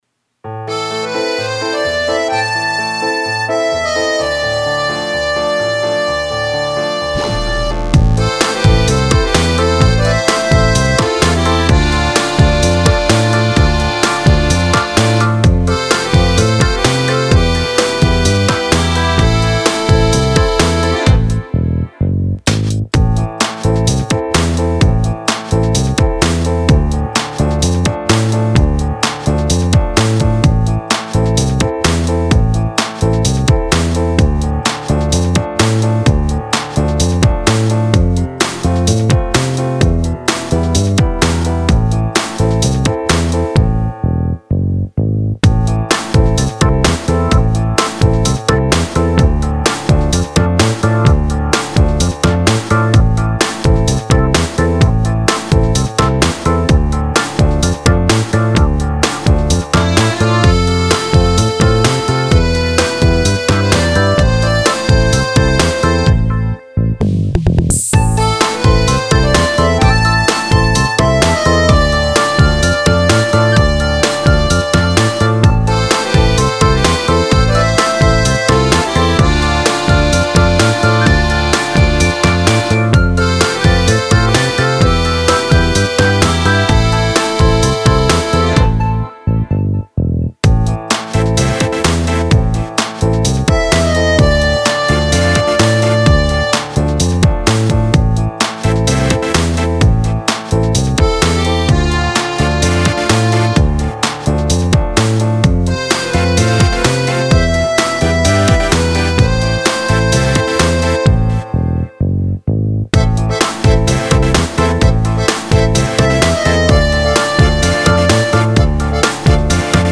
an easy-going accordion melody
tango
instrumental
ambient
polka
pop
accordeon
happy
passionate
melancholic
romantic
quiet
sentimental